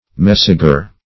messager - definition of messager - synonyms, pronunciation, spelling from Free Dictionary Search Result for " messager" : The Collaborative International Dictionary of English v.0.48: Messager \Mes"sa*ger\, n. [OE.]